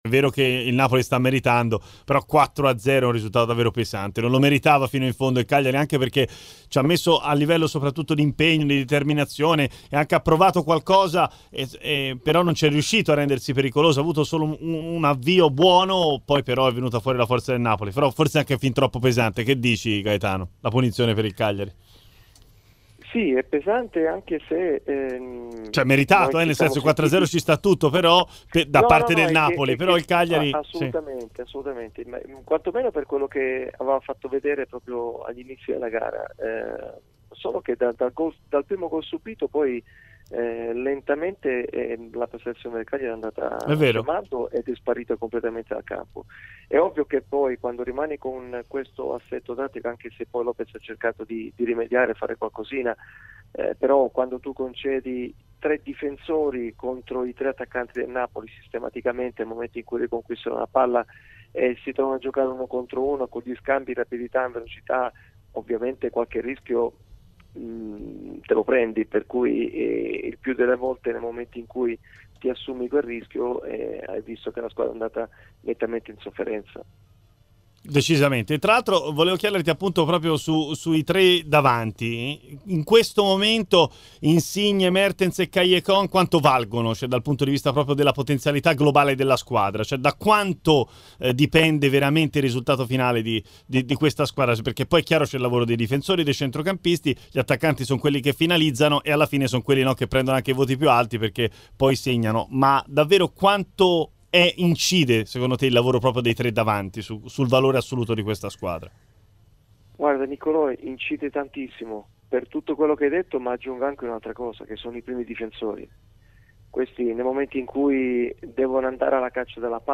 a commento del secondo tempo di Cagliari-Napoli. In studio